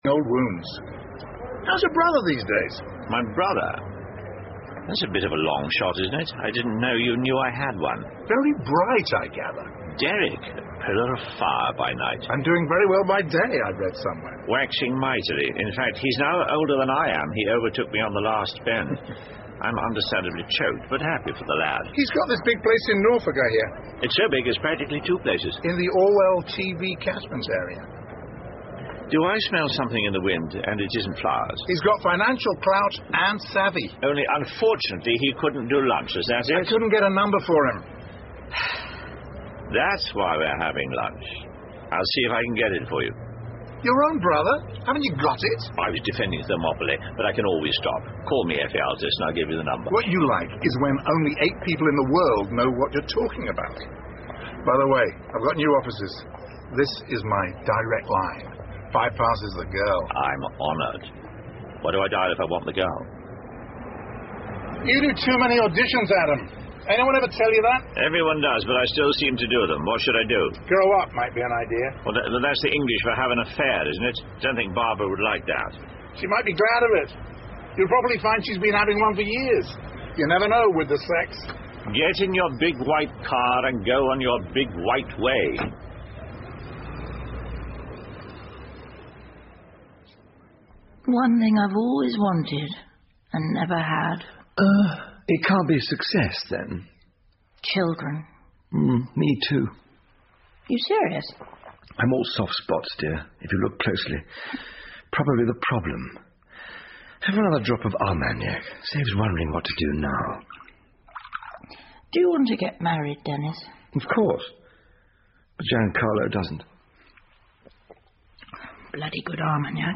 英文广播剧在线听 Fame and Fortune - 10 听力文件下载—在线英语听力室